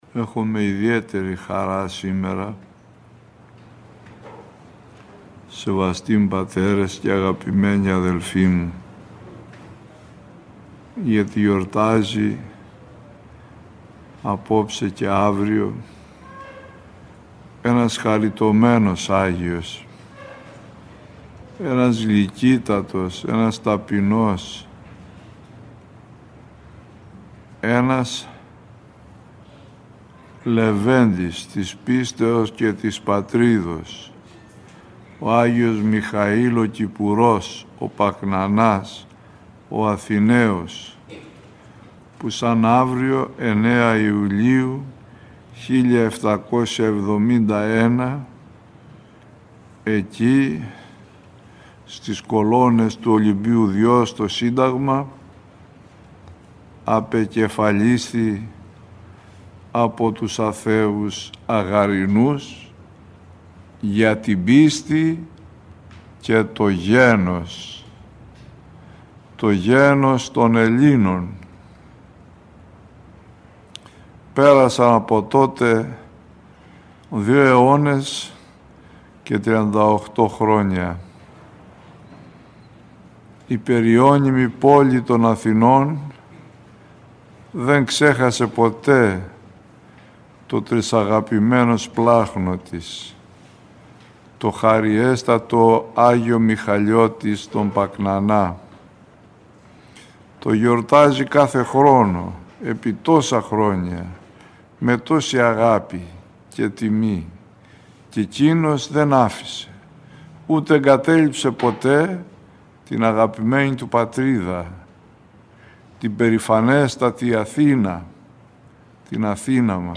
09 Ιουλίου, ο Νεομάρτυς Άγιος Μιχαήλ Ο Πακνανάς – Ομιλία